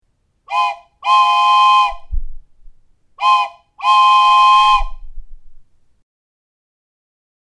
Train whistle
A deceptively realistic sound!
• Material: Wood